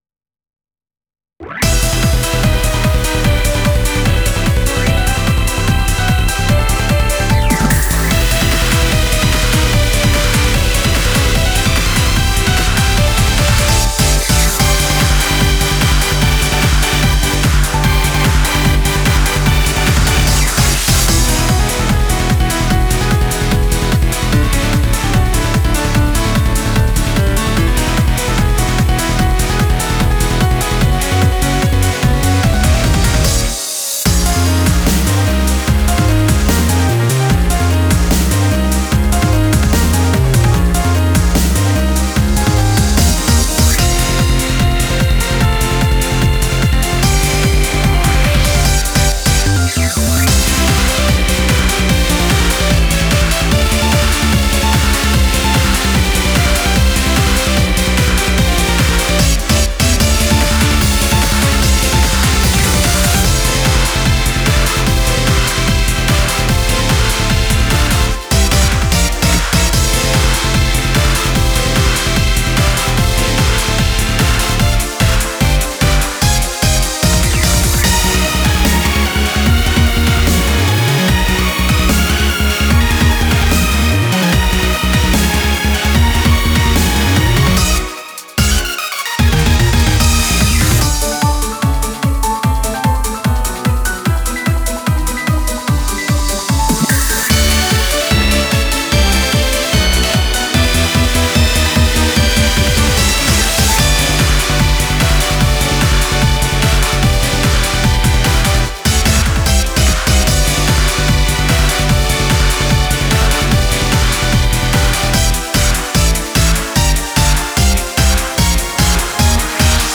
BPM74-148
MP3 QualityMusic Cut